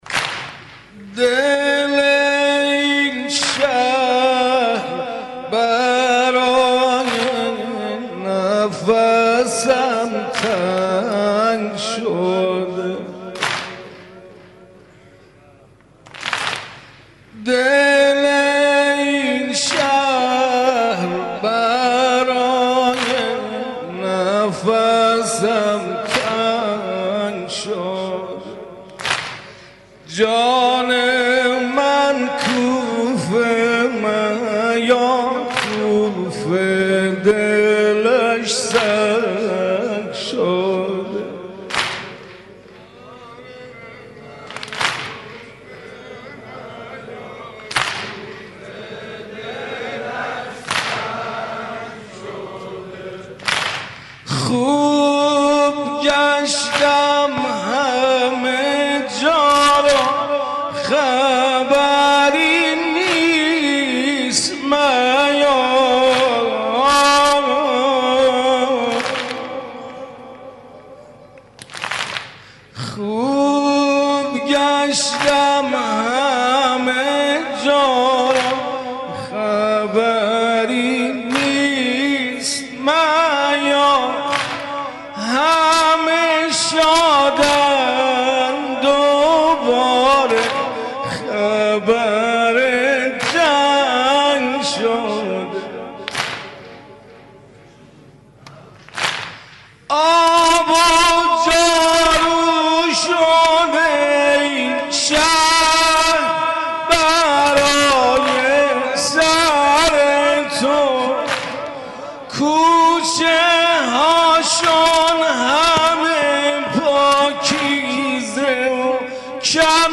مناسبت : شب اول محرم